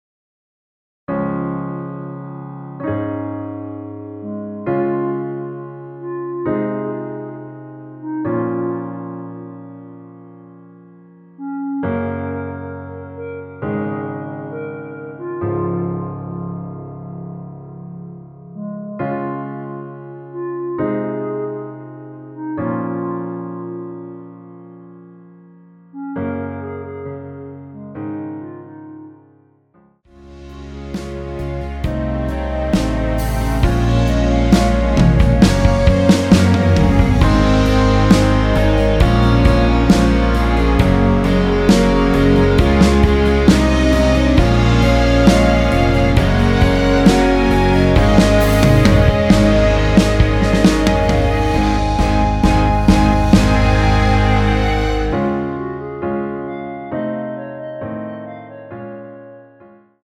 전주없이 시작하는 곡이라 1마디 전주 만들어 놓았습니다.(미리듣기 참조)
원키 멜로디 포함된 MR입니다.(미리듣기 확인)
Db
앞부분30초, 뒷부분30초씩 편집해서 올려 드리고 있습니다.